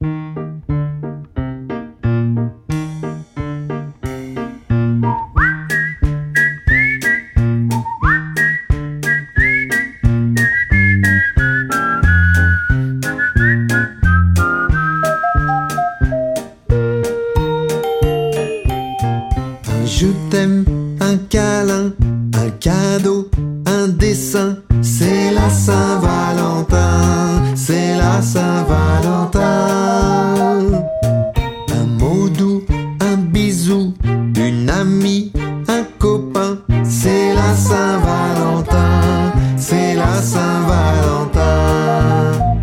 sing-along